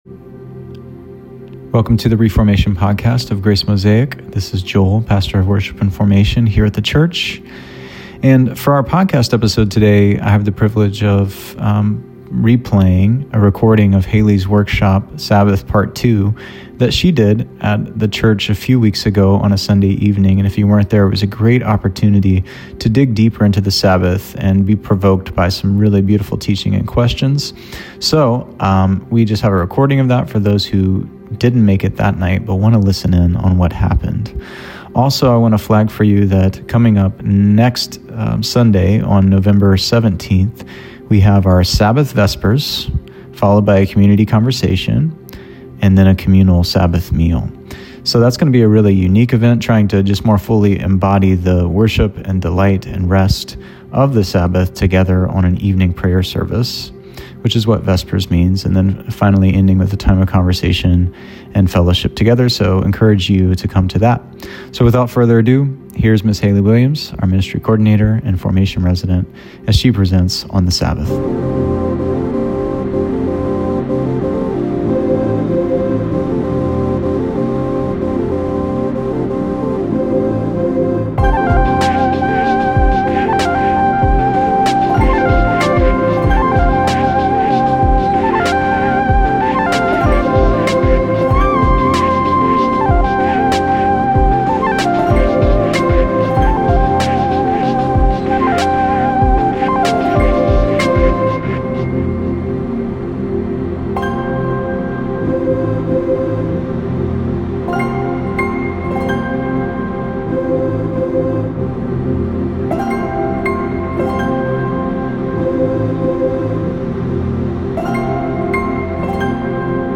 This episode is a recording of our second Daily Formation Project workshop on the practice of Sabbath.